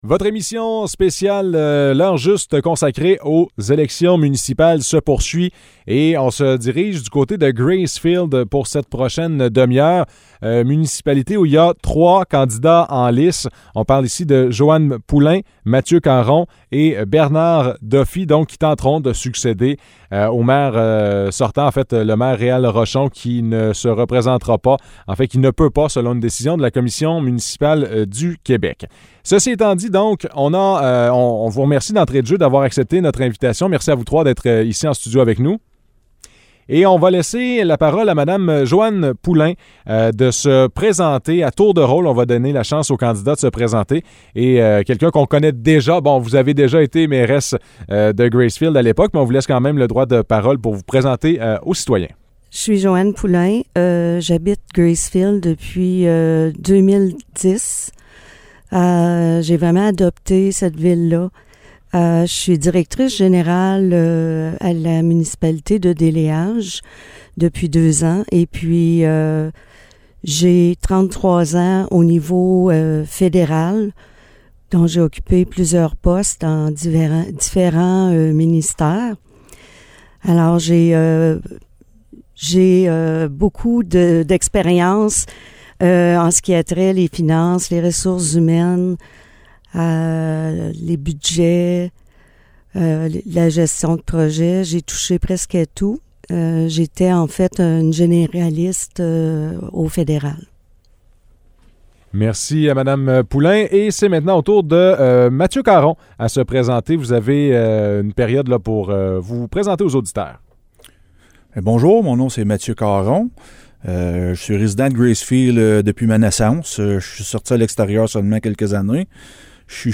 Entrevue avec les candidats à la mairie de Gracefield
entrevue-avec-les-candidats-a-la-mairie-de-gracefield.mp3